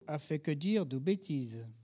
parole, oralité
Collectif atelier de patois
Catégorie Locution